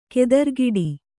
♪ kedargiḍi